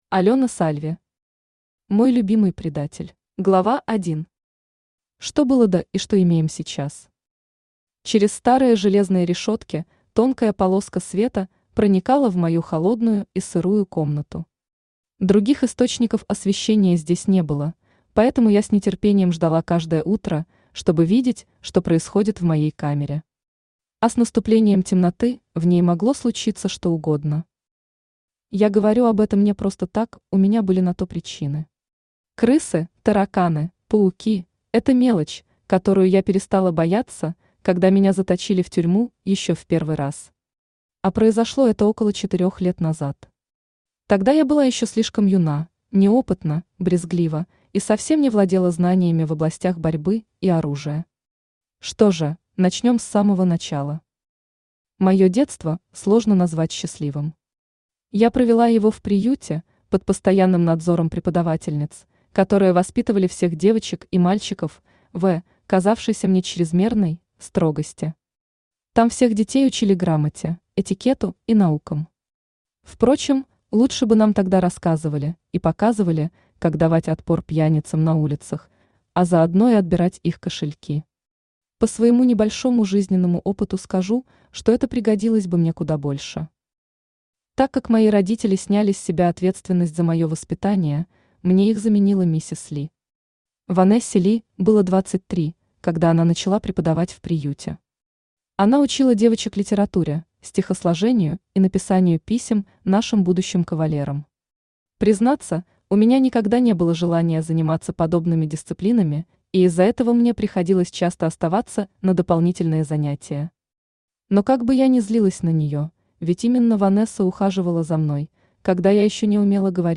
Аудиокнига Мой любимый предатель | Библиотека аудиокниг
Aудиокнига Мой любимый предатель Автор Алёна Сальви Читает аудиокнигу Авточтец ЛитРес.